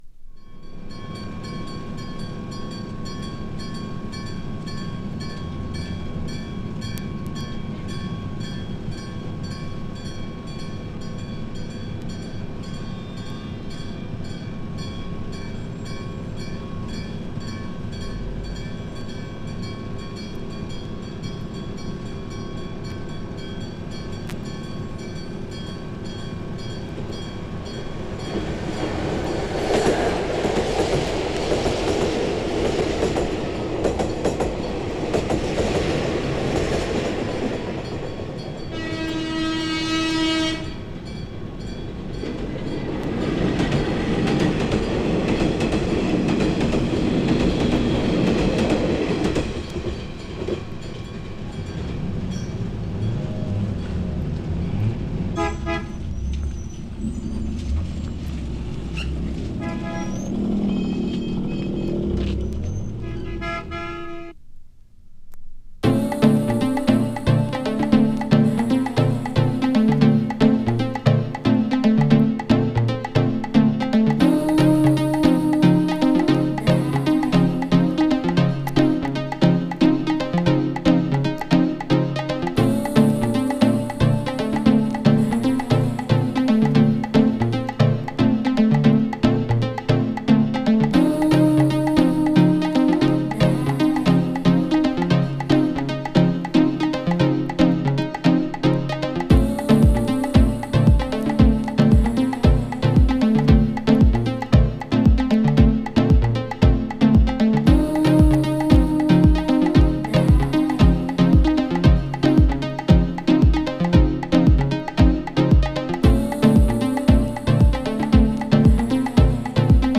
deep houseのdeepを独自に解釈。
未来が小躍りしながら朝に来る、coffee屋さんのmodern synth houseなA面。
古代ナイル川流域のparty ? をout putしたかのようなピラミッド建設系houseなB面。